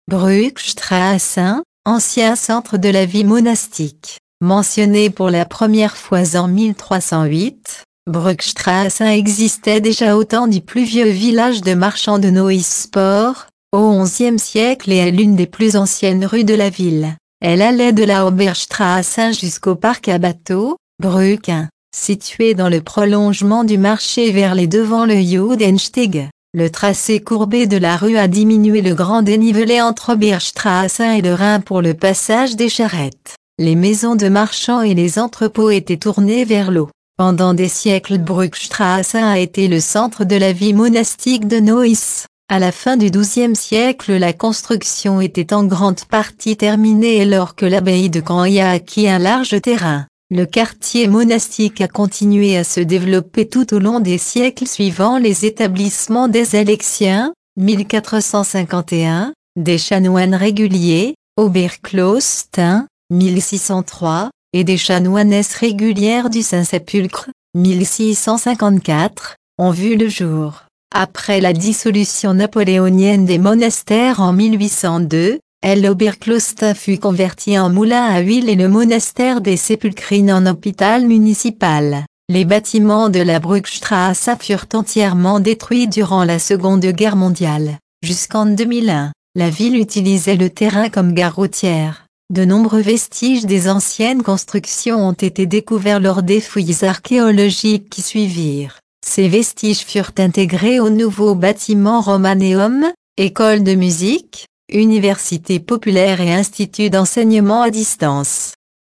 Audio Guide (français)